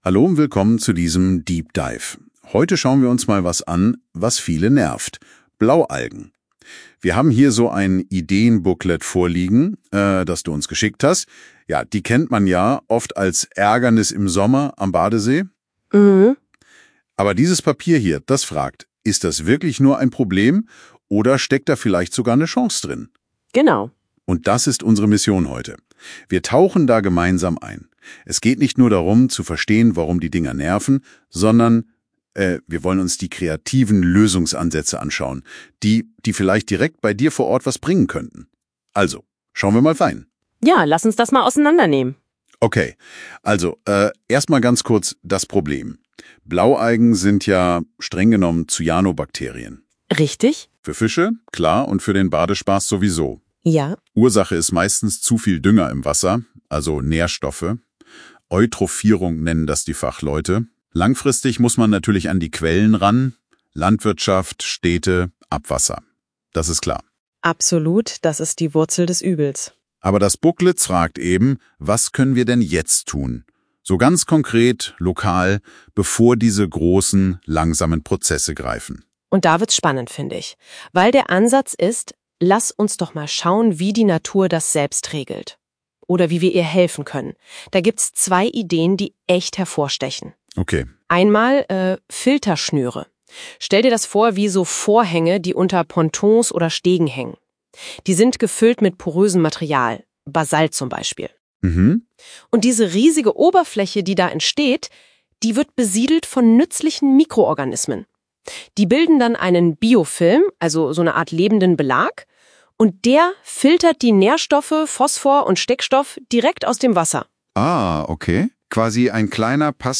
Audiozuammenfassung Blaualgen NHD
DeepDive by Gemini